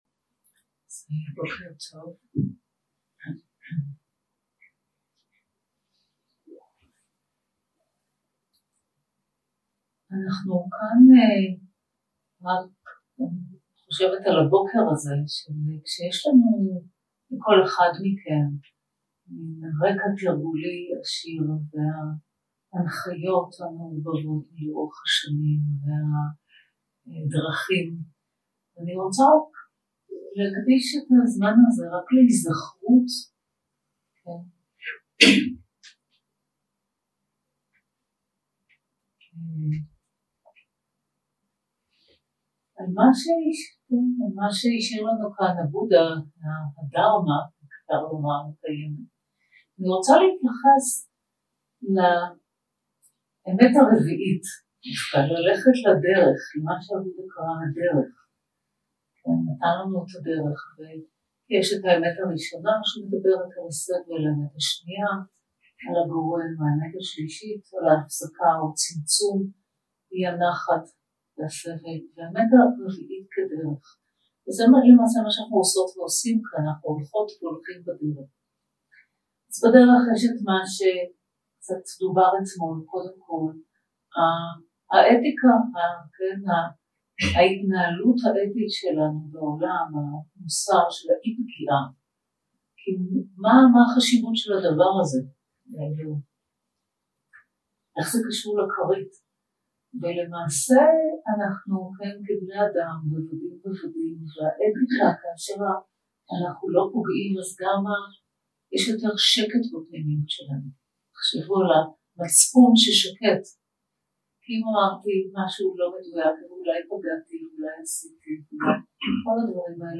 שיחת הנחיות למדיטציה